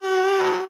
Minecraft Version Minecraft Version snapshot Latest Release | Latest Snapshot snapshot / assets / minecraft / sounds / mob / ghast / moan1.ogg Compare With Compare With Latest Release | Latest Snapshot
moan1.ogg